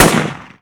sol_reklam_link sag_reklam_link Warrock Oyun Dosyalar� Ana Sayfa > Sound > Weapons > K1 Dosya Ad� Boyutu Son D�zenleme ..
WR_fire.wav